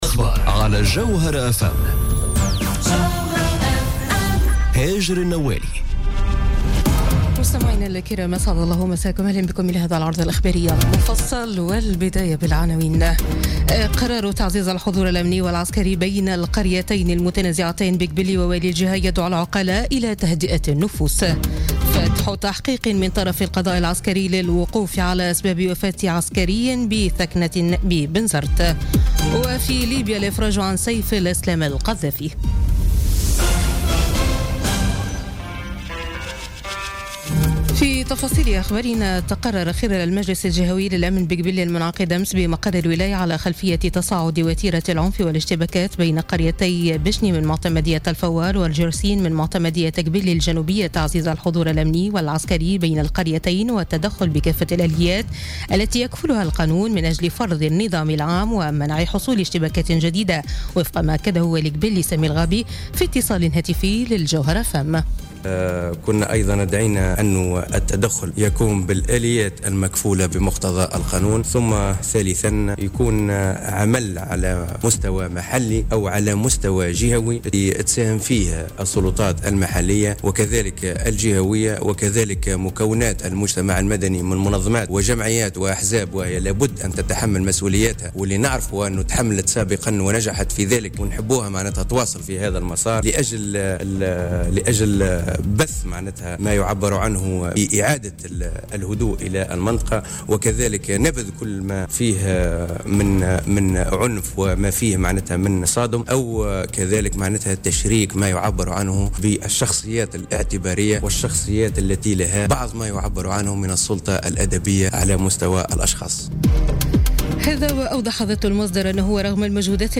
نشرة أخبار منتصف الليل ليوم الأحد 11 جوان 2017